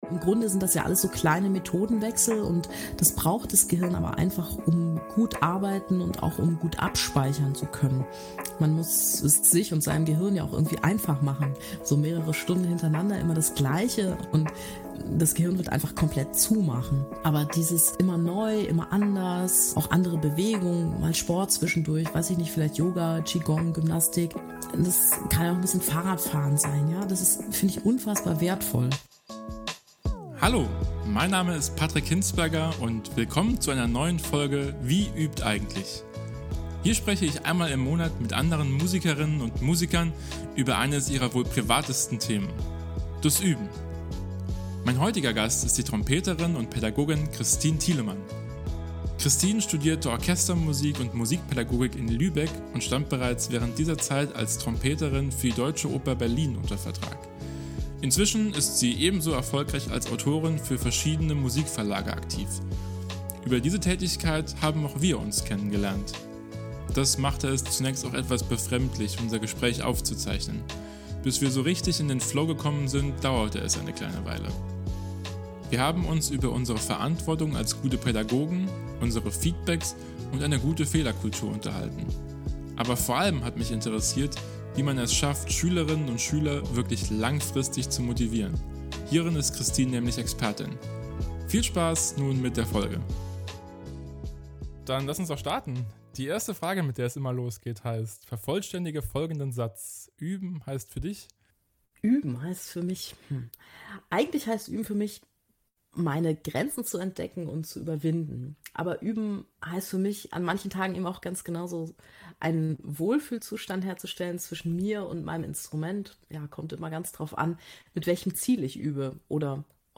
Wir haben uns über unsere Verantwortung als Pädagog*innen, unser Feedbacks und eine gute Fehler-Kultur unterhalten. Aber vor allem hat mich interessiert, wie man es schafft Schülerinnen und Schüler wirklich zu langfristig motivieren.